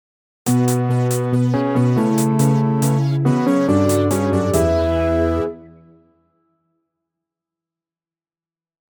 Retro track for transitions & stingers.